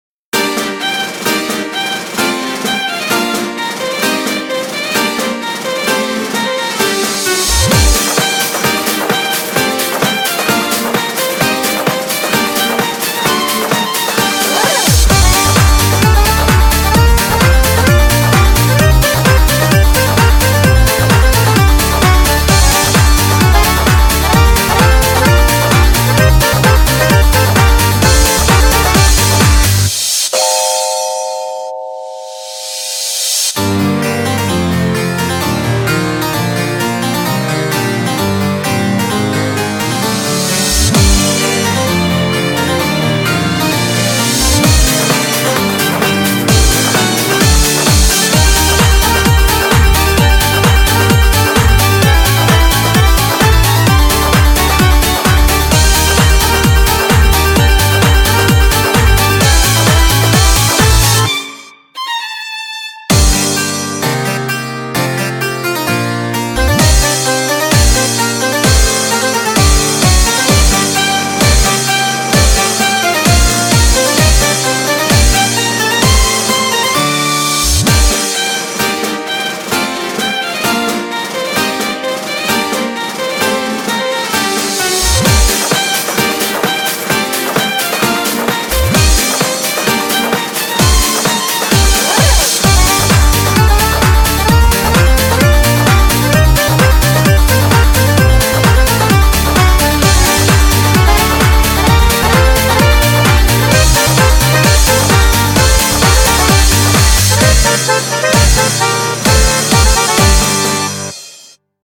BPM130
Audio QualityPerfect (High Quality)
Song type: Otoge